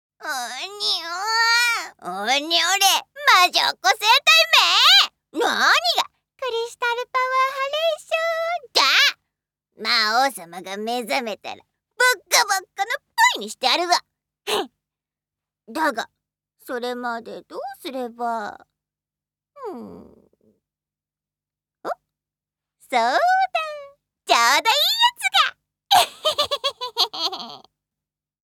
ボイスサンプル
悪者マスコット